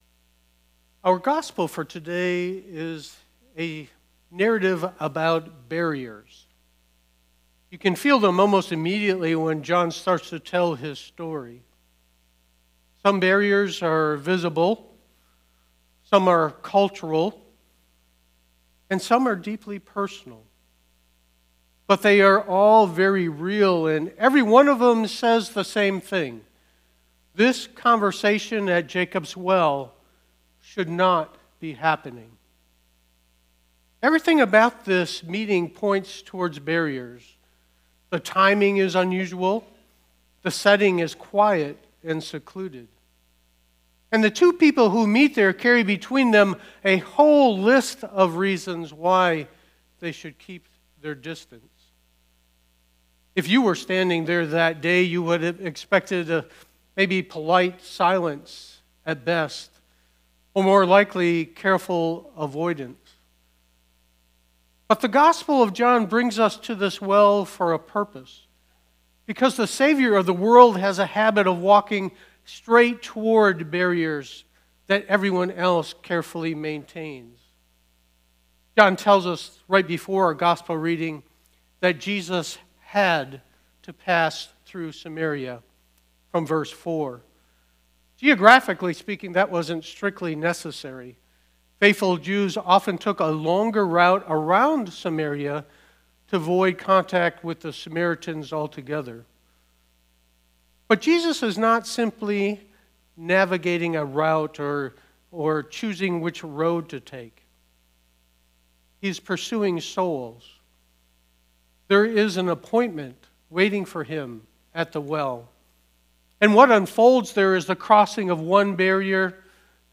Sermons – Page 3